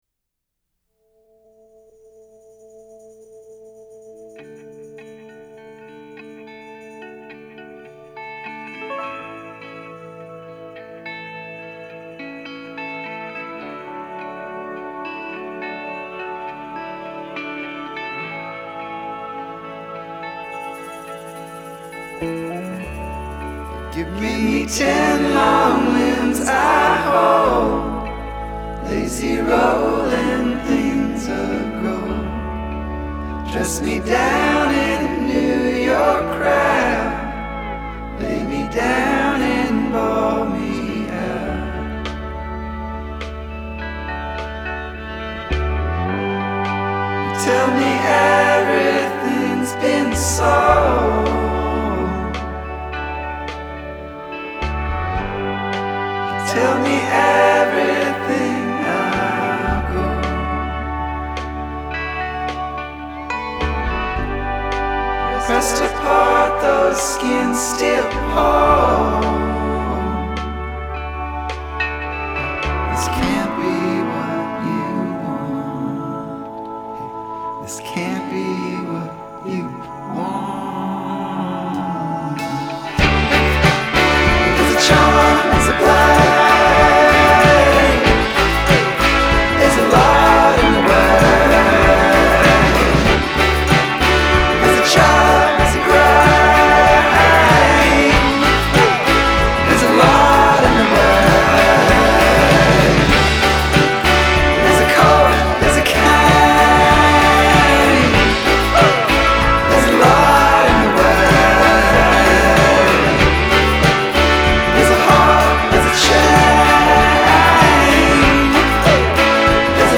Indie-folk troubadour
Off-centered soundscapes layer the alt country sound